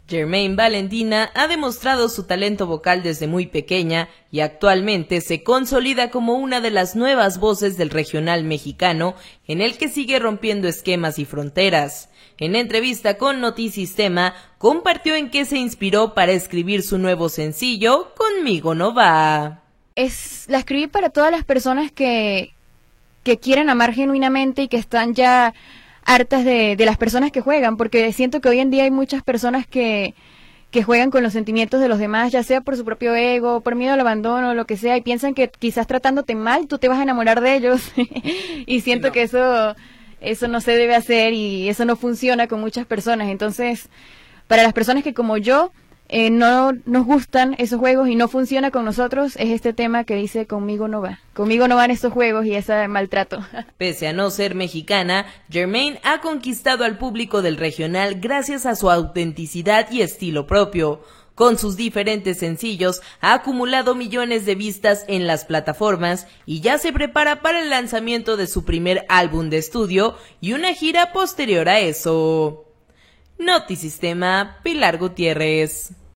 En entrevista con notisistema, compartió en que se inspiro para escribir su nuevo sencillo “Conmigo no va”.